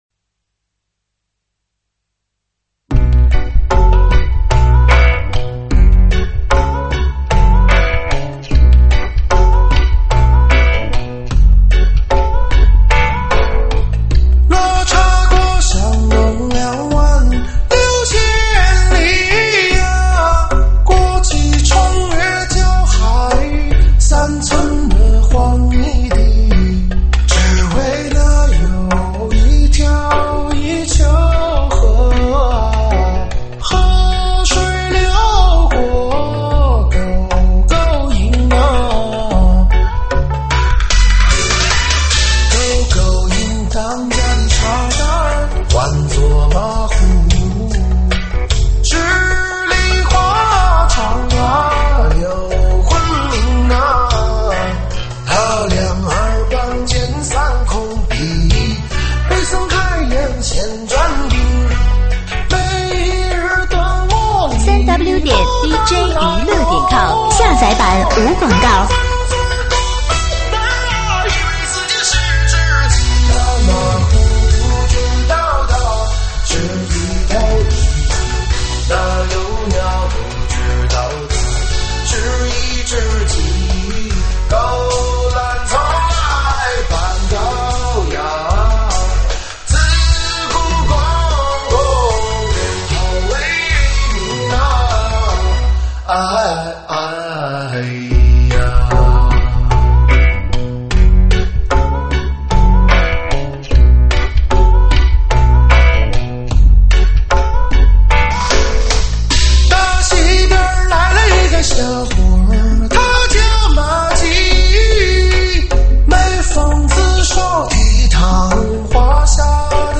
中文舞曲